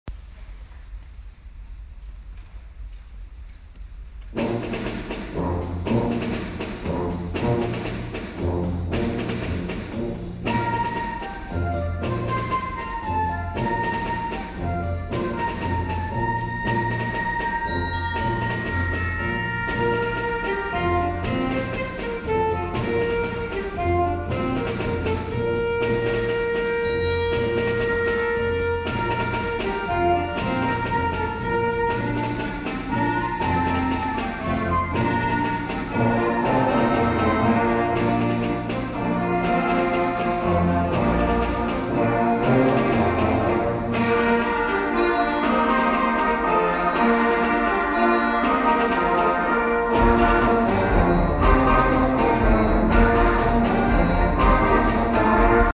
１０月２０日（木）市内音楽発表会に吹奏楽部が参加して柏市文化会館のホールに美しい音色を響かせました。